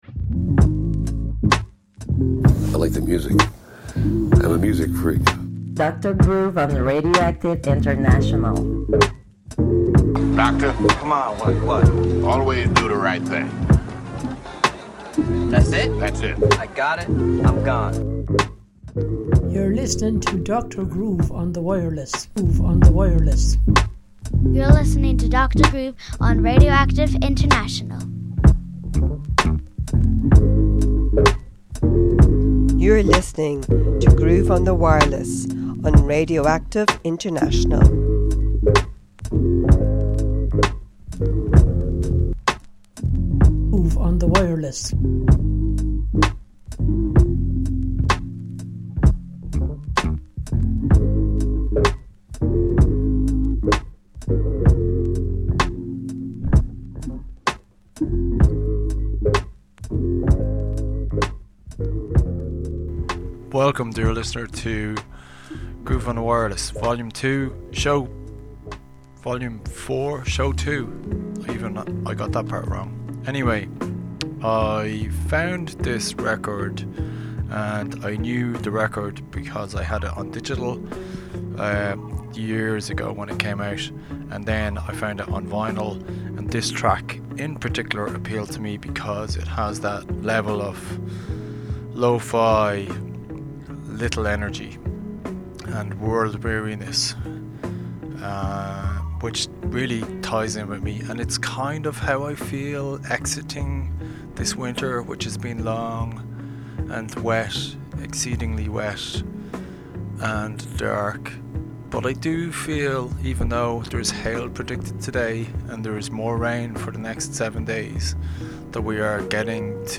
This show starts slow, and never really get’s hectic.
Then more sounds in keeping with the low energy mood.